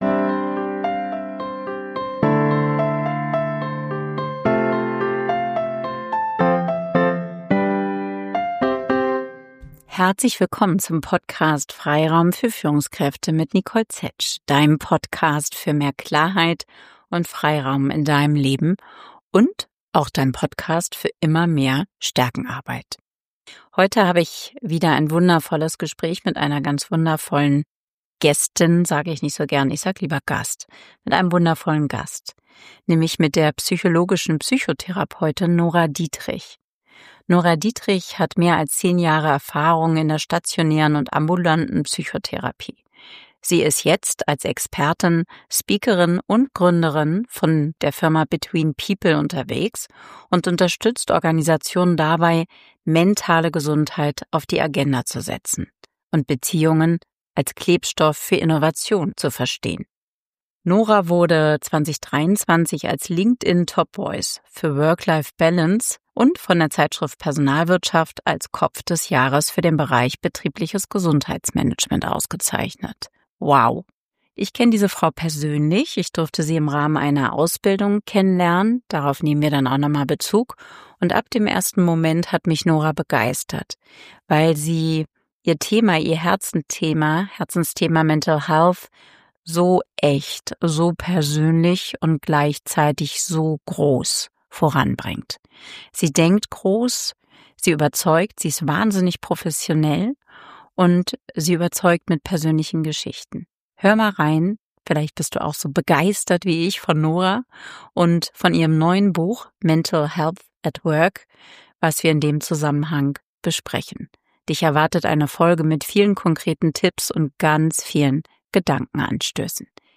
In dieser Folge sprechen wir über konkrete Wege zu einem gesunden Ich, einem gesunden Team – und über Führung, die beides möglich macht. Fünf zentrale Impulse aus dem Gespräch: 1.